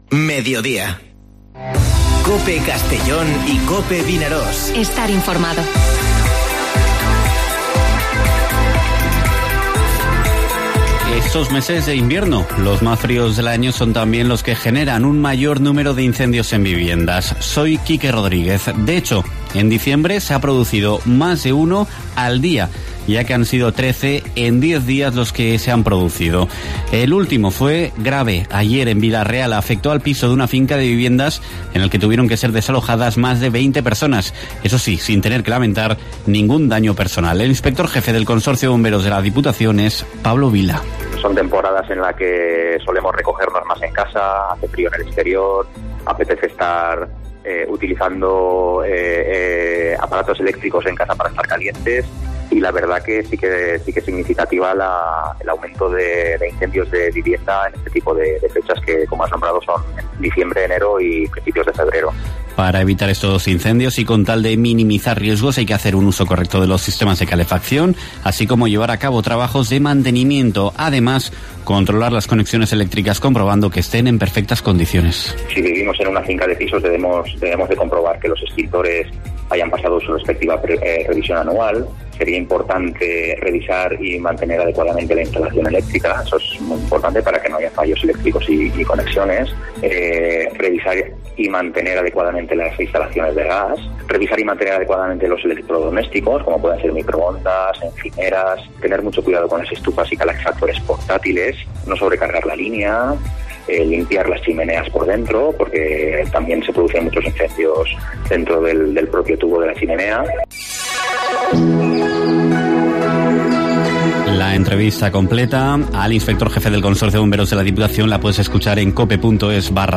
Informativo Mediodía COPE en la provincia de Castellón (11/12/2020)